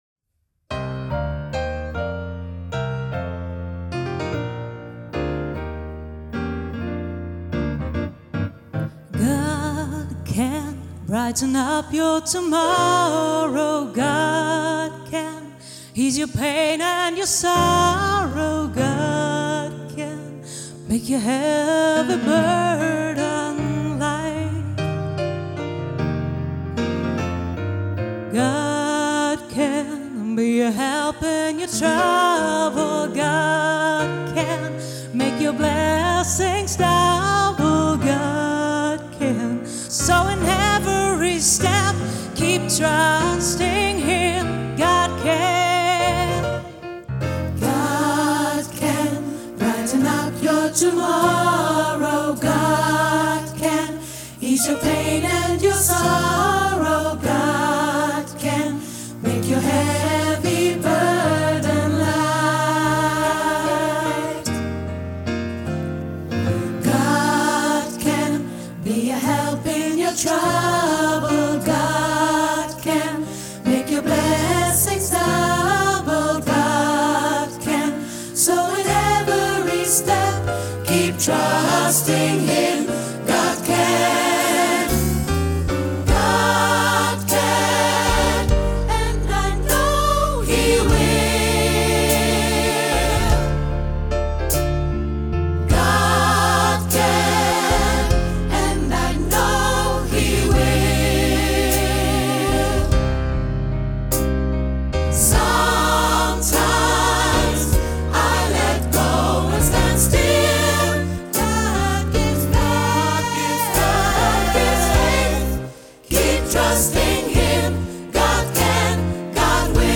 • SAB, Solist + Piano